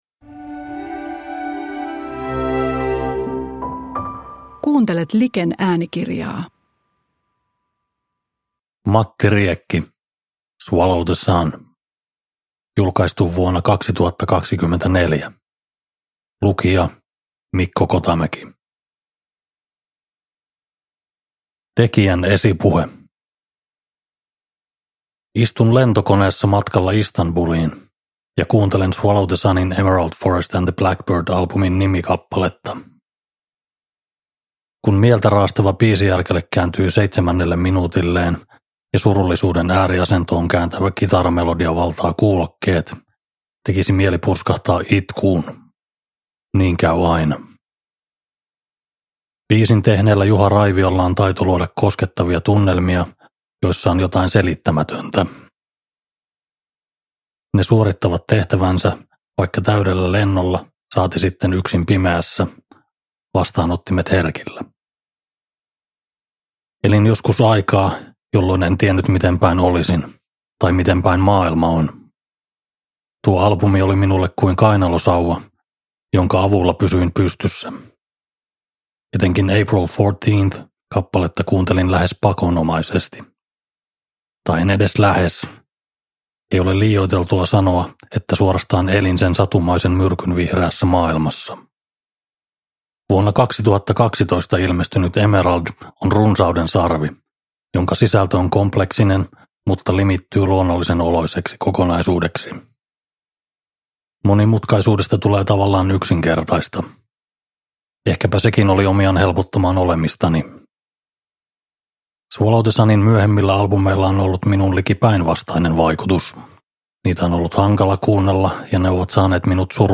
Swallow the Sun – Ljudbok